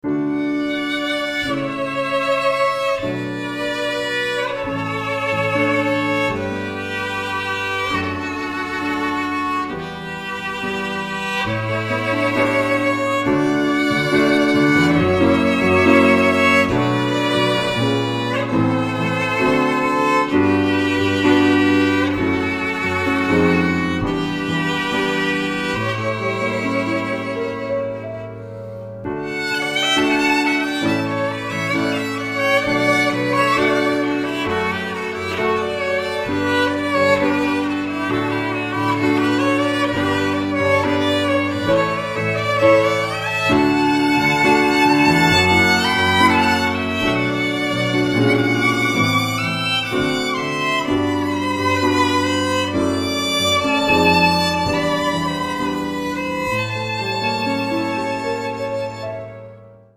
THG Alumnus   on violin and piano